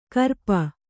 ♪ karpa